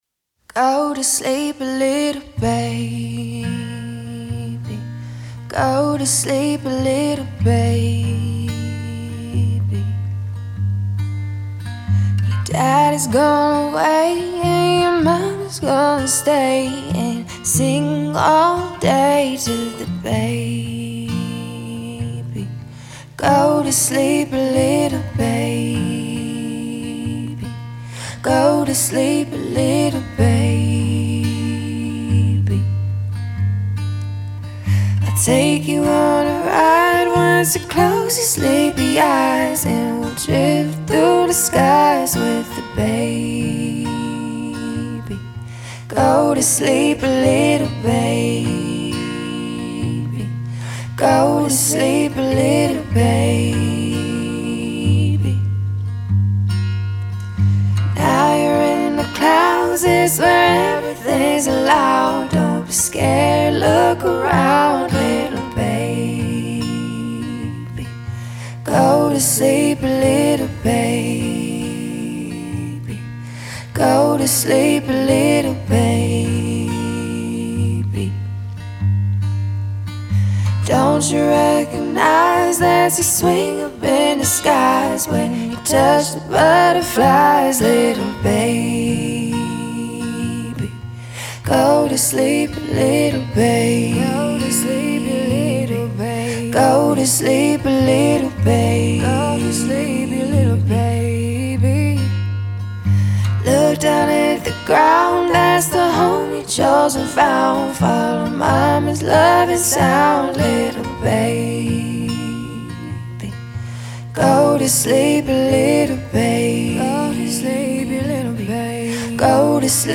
это трек в жанре R&B с элементами поп